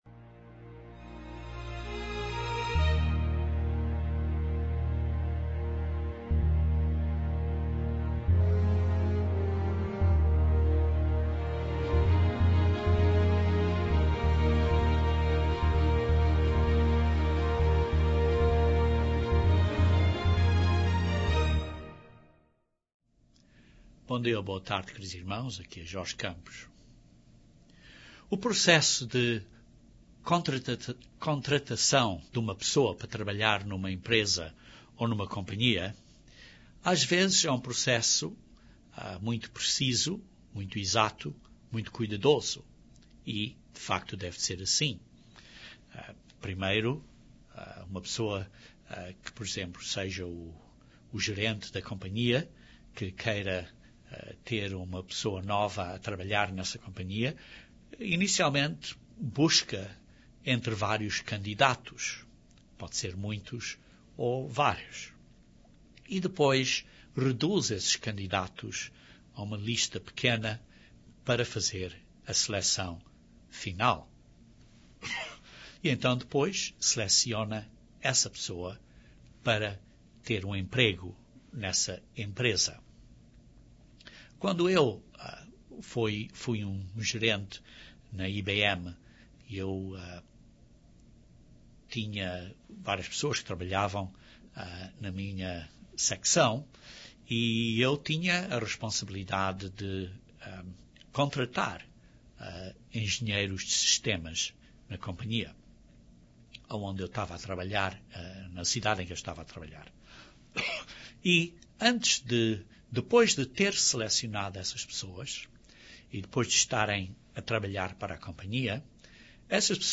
Jesus dá ensinamentos para líderes. Este sermão descreve a missão que Jesus dá aos Seus líderes.